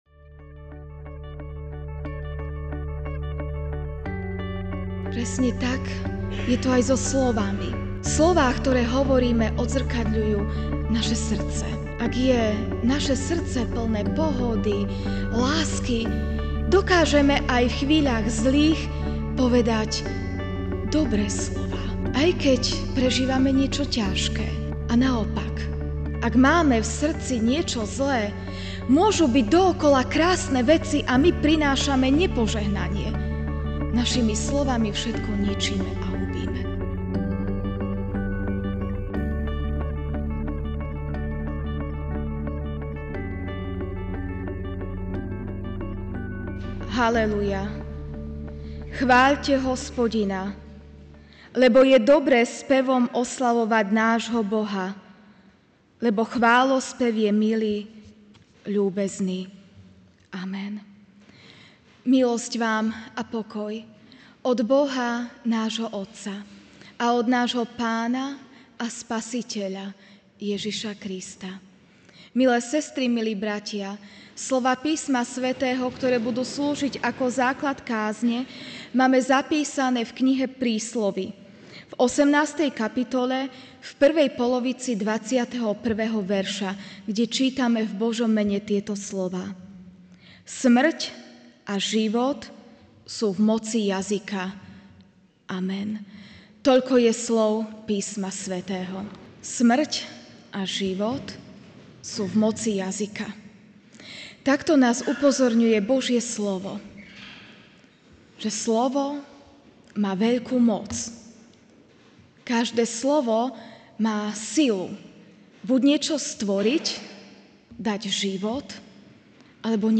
Ranná kázeň: Sila slova. (Príslovia 18,21a)